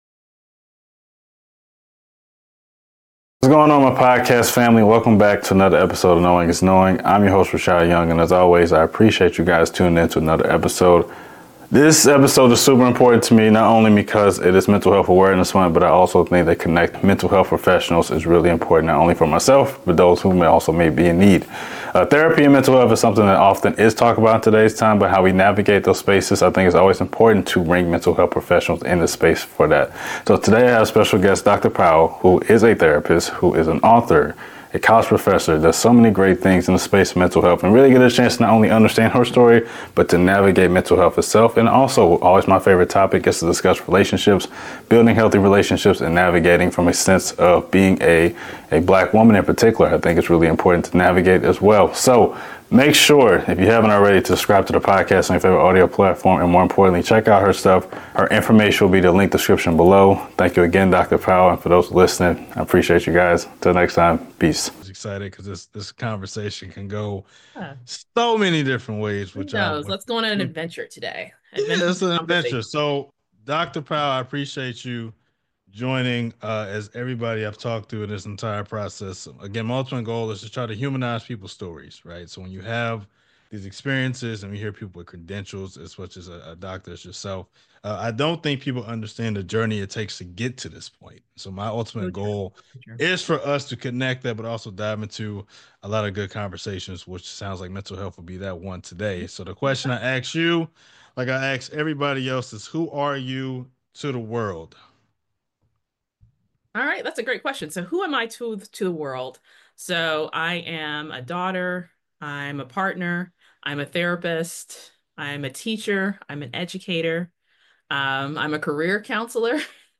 Interview – Knowing Is Knowing Podcast